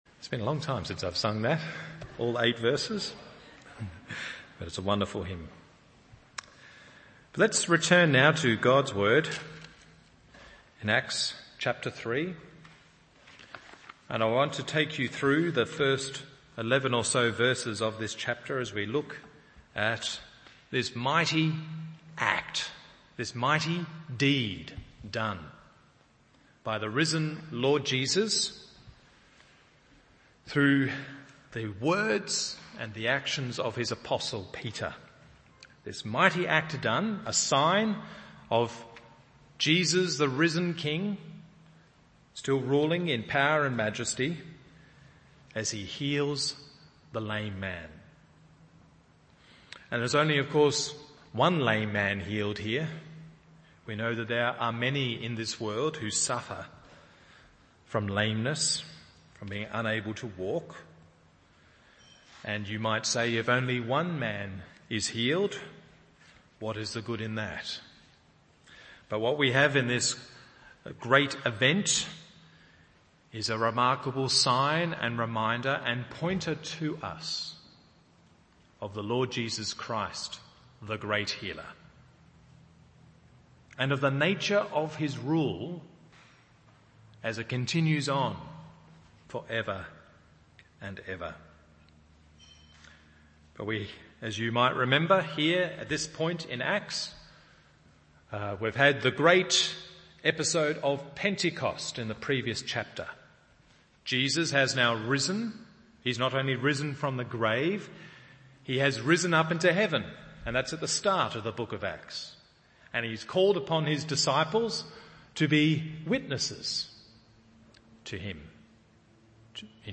Evening Service Acts 3:1-10 1.